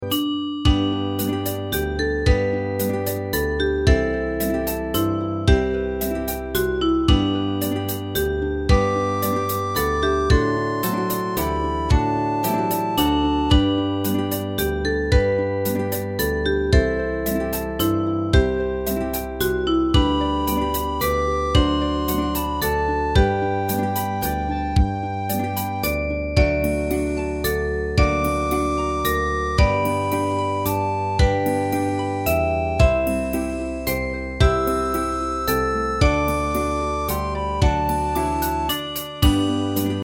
大正琴の「楽譜、練習用の音」データのセットをダウンロードで『すぐに』お届け！
Ensemble musical score and practice for data.